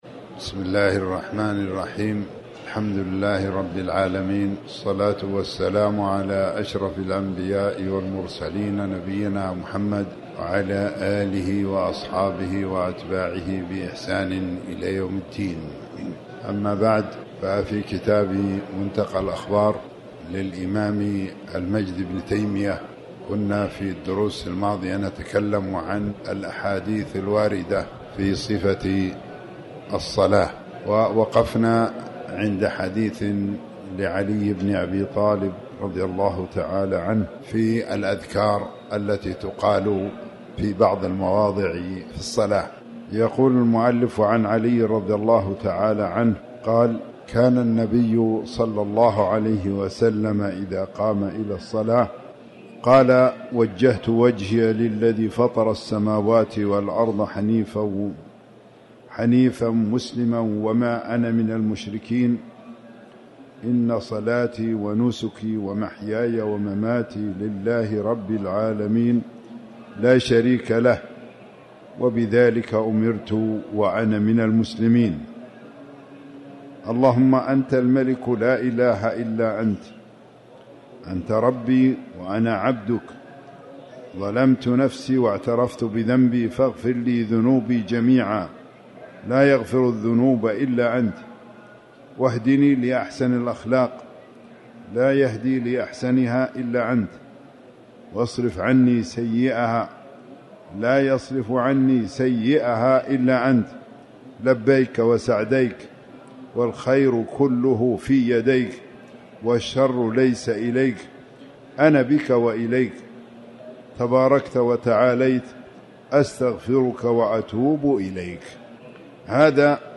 تاريخ النشر ٢ رمضان ١٤٤٠ هـ المكان: المسجد الحرام الشيخ